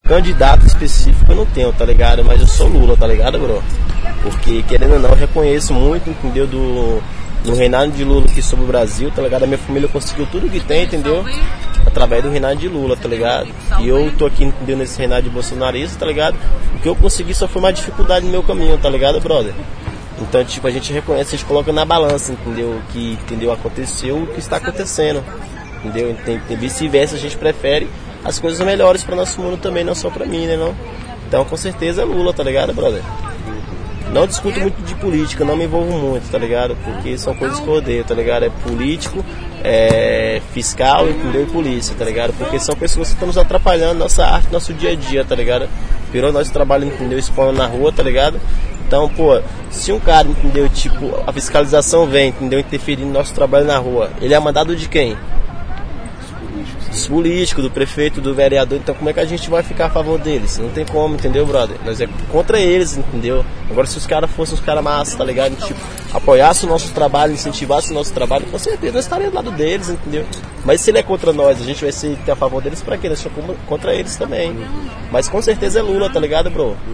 Depoimento em áudio